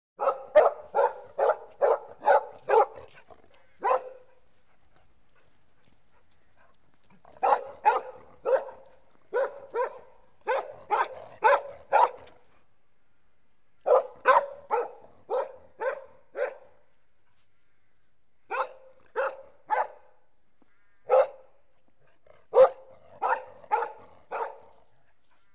Dog_Barking-01.wav